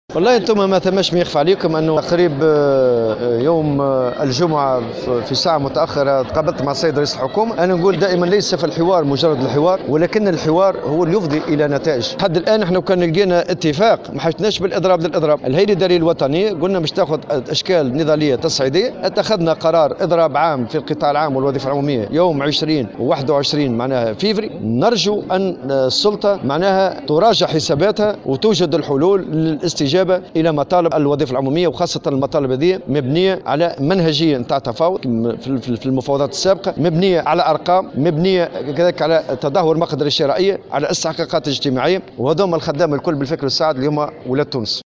قال الأمين العام للاتحاد العام التونسي للشغل نور الدين الطبوبي في تصريح لمراسلة الجوهرة" اف ام" اليوم الأحد إنهم لم يعلنوا الإضراب لرغبتهم فيه بل بعد تعثر المفاوضات وعدم توصلهم لإتفاق مع رئيس الحكومة بعد لقاء جمعه به يوم الجمعة الماضي.